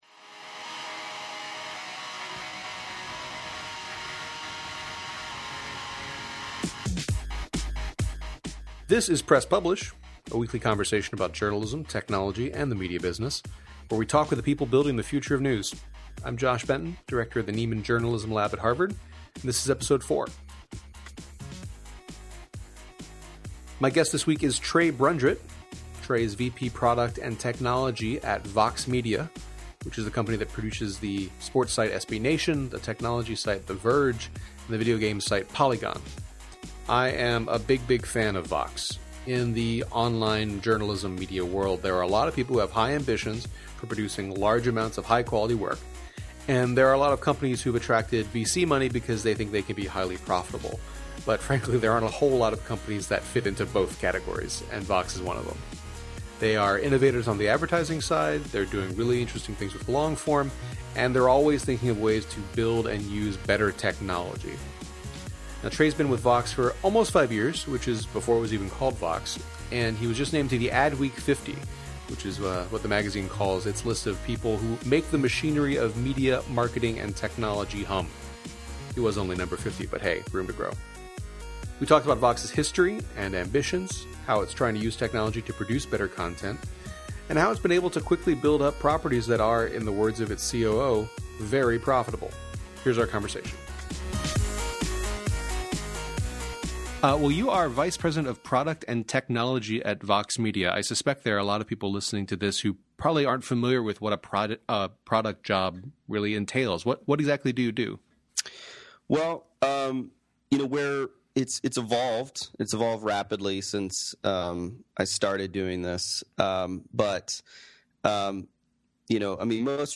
We had a good, wide-ranging conversation about Vox’s evolution and where it’s headed in 2013.